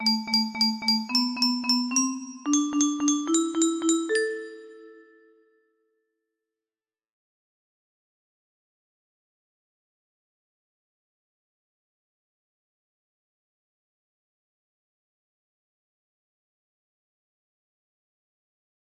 Melhevan music box melody